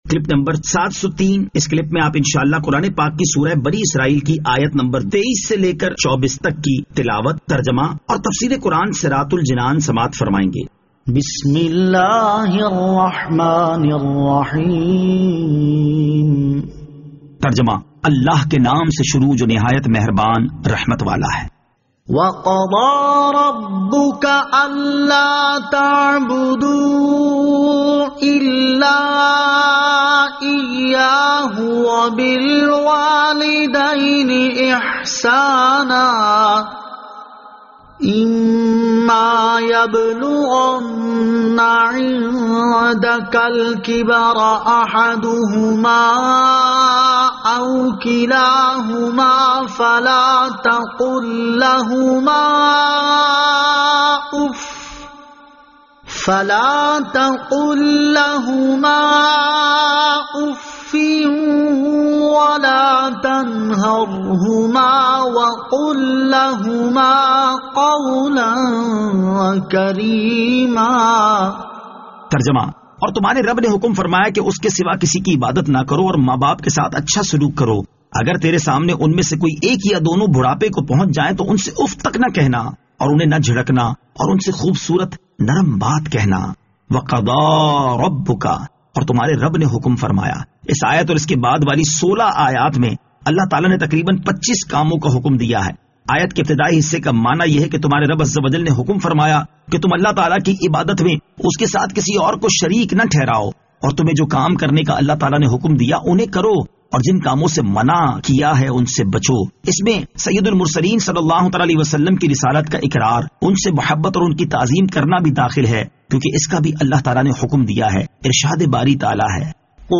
Surah Al-Isra Ayat 23 To 24 Tilawat , Tarjama , Tafseer